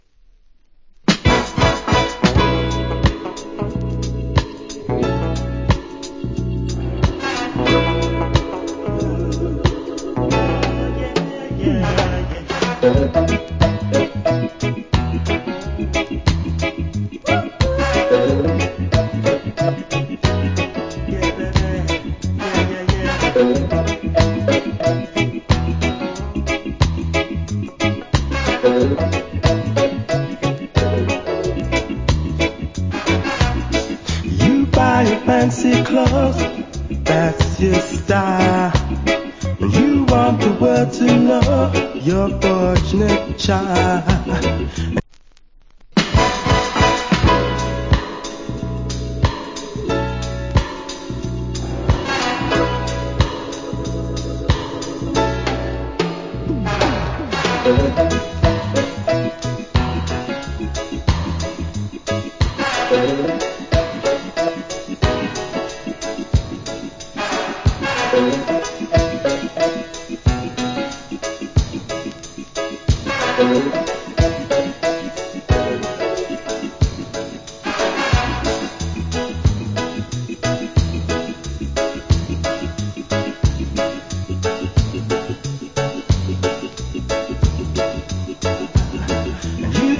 Nice Lovers Rock Vocal.